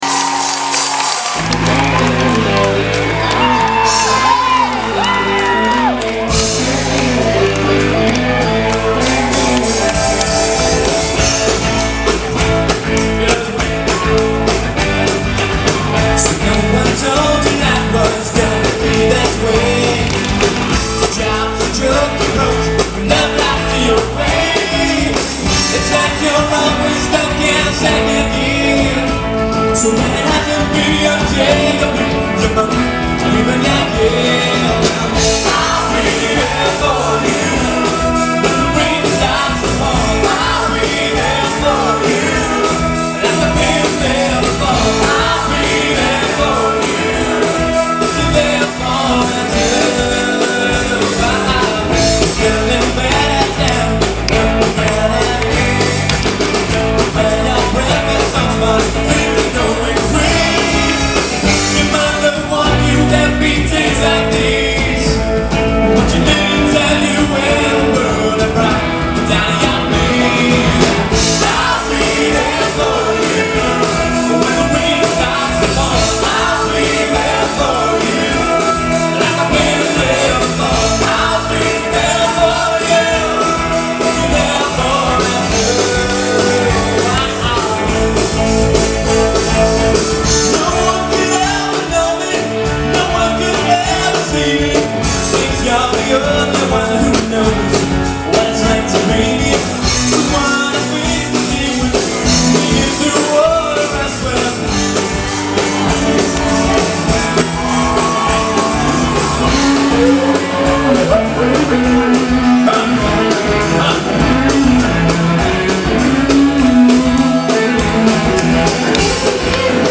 Band Set